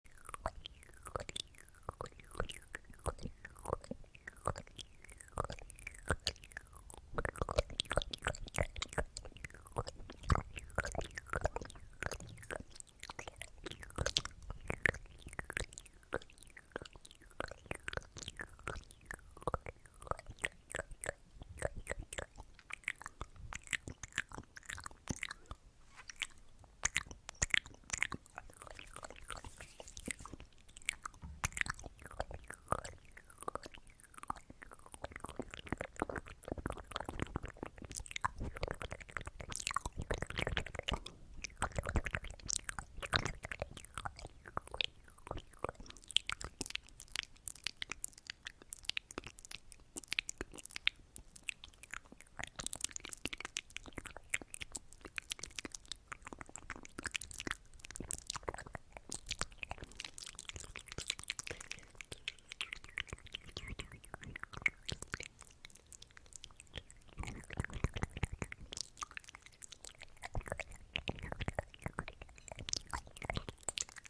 Mouth sounds and hand movements